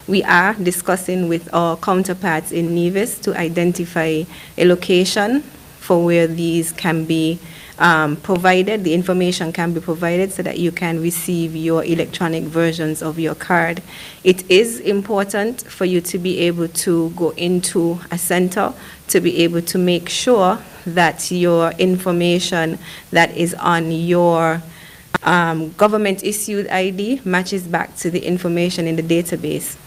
This was one of the questions posed at the NEOC briefing on Wednesday September 1st.
Federal Minister of Health, Akilah Byron-Nisbett provided this response: